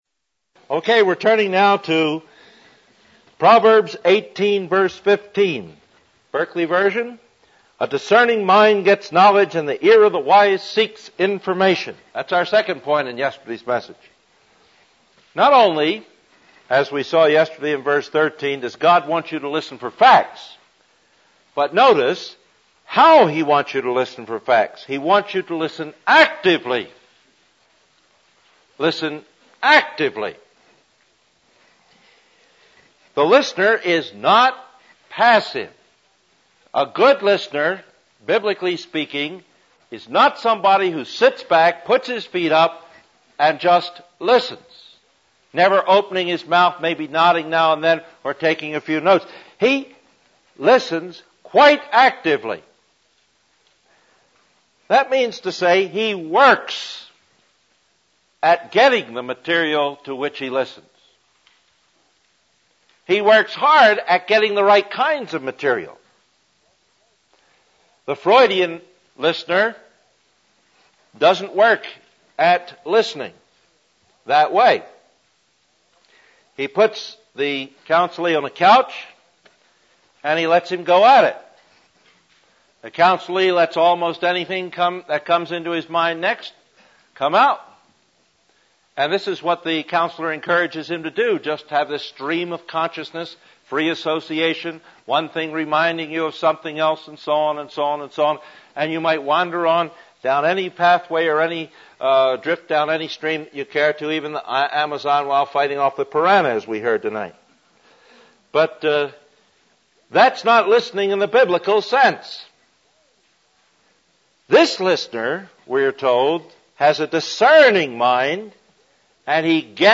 This is the third lecture from a classic series on Biblical Counseling Principles